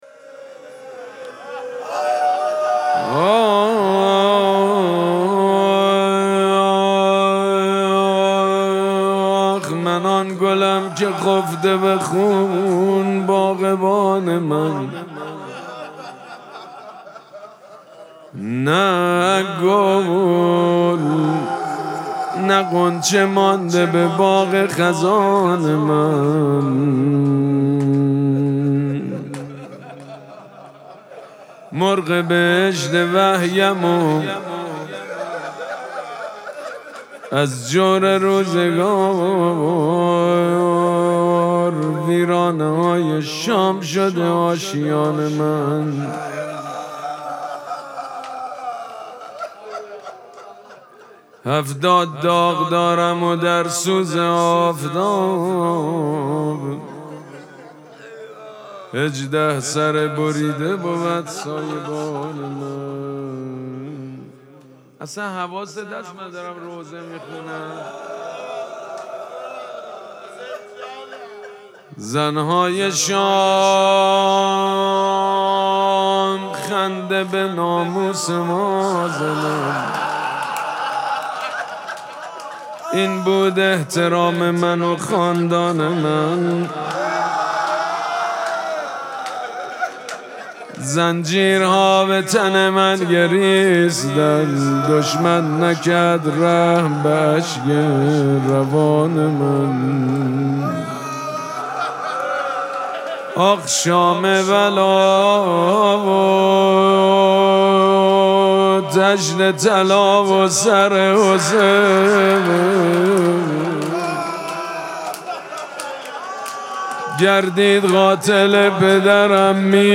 مراسم مناجات شب چهارم ماه مبارک رمضان
روضه
حاج سید مجید بنی فاطمه